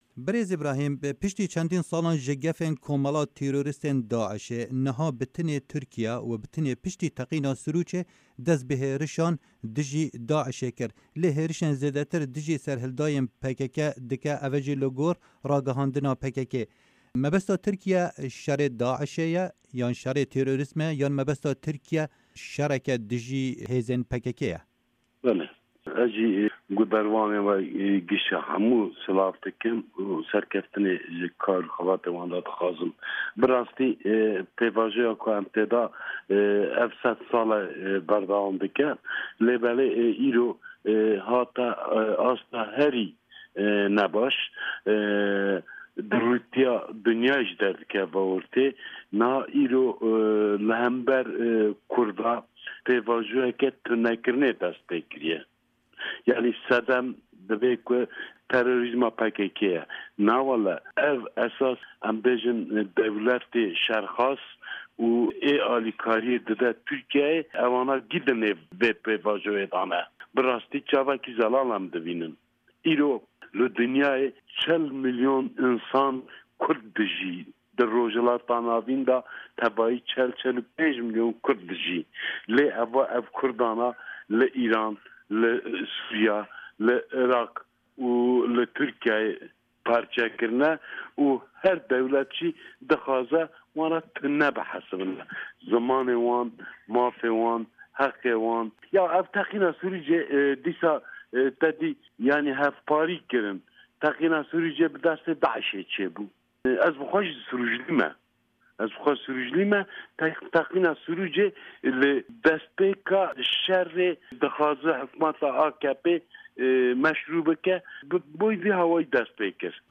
Di hevpeyvînekê de ligel Dengê Amerîka, endamê berê yê perlemana Tirkiye Îbrahîm Bînîcî got, hikûmeta AKPbi behana şerî teror ê û şerê Daîş ê êrşan dijî PKKê dike.
Hevpeyvin digel Îbrahîm Bînîcî